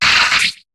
Cri d'Arakdo dans Pokémon HOME.